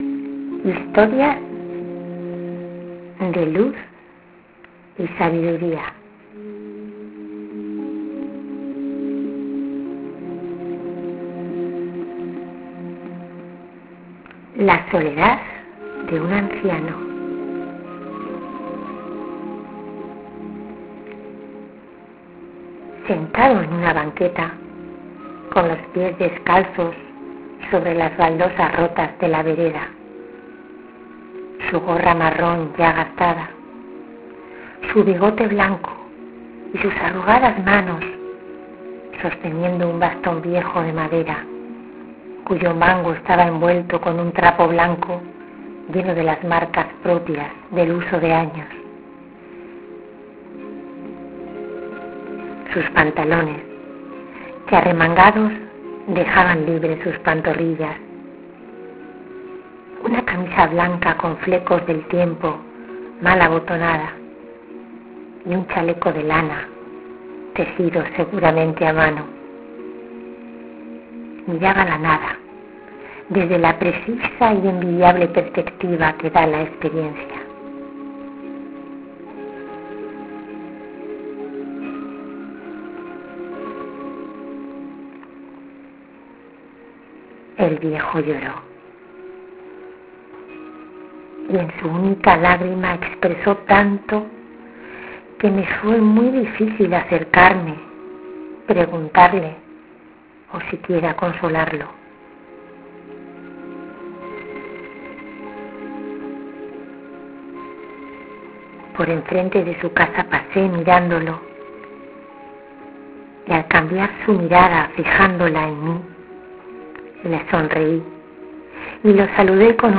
Ahora puedes bajarte esta historia narrada